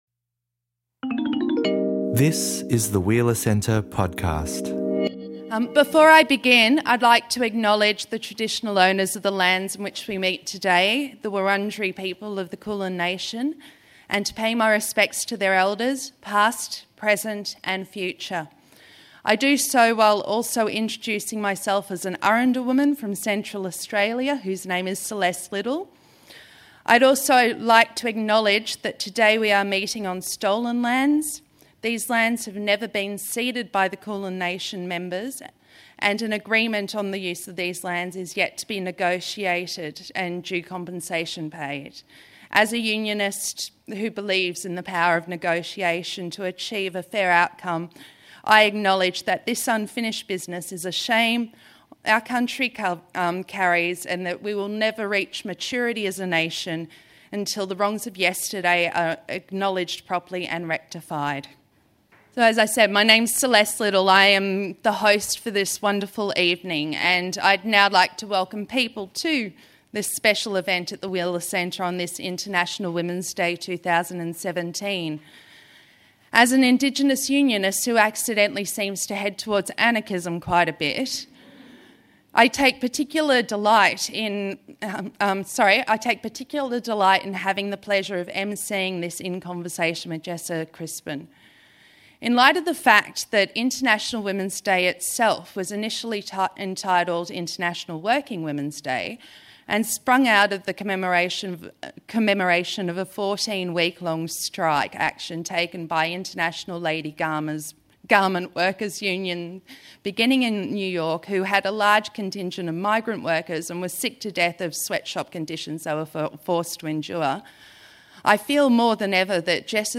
Following a short presentation from Crispin, the pair discuss representation, the Second Wave, sexuality, ageism, beauty and much more.